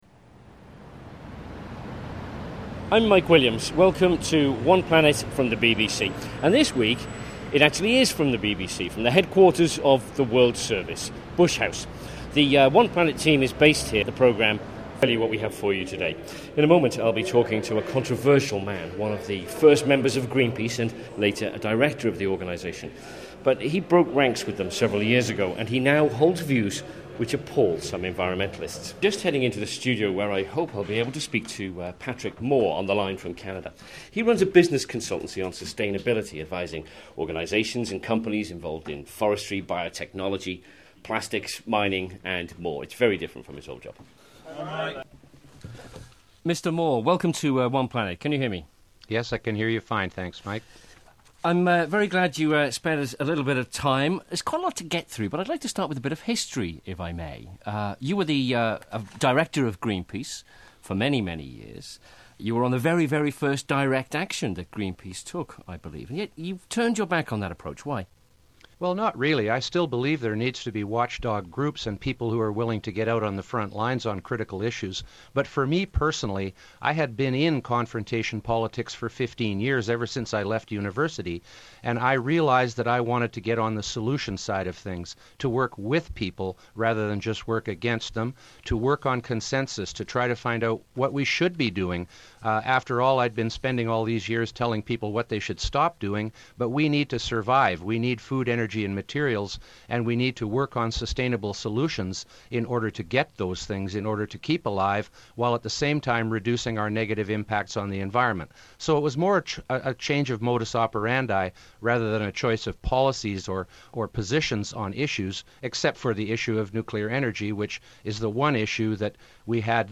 Greenpeace_interview